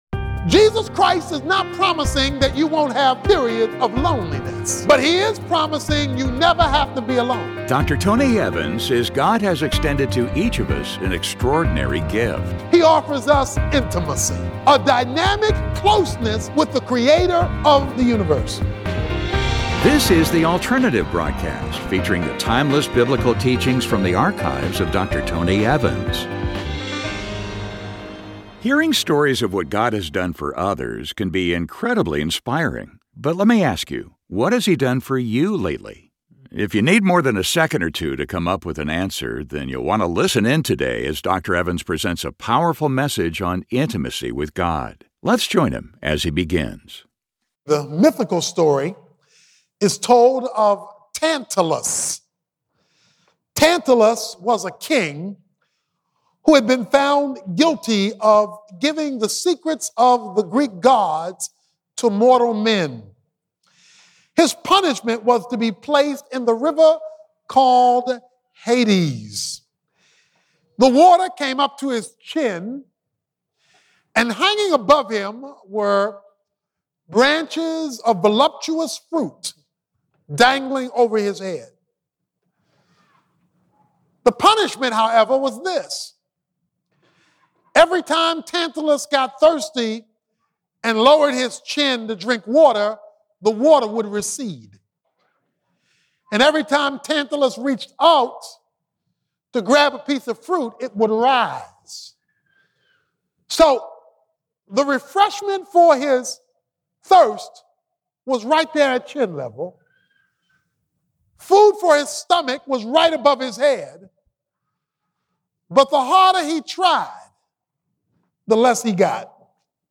If you need more than a second or two to come up with an answer, then you'll want to listen to this sermon from Dr. Tony Evans as he presents a powerful message on building an intimate relationship with God.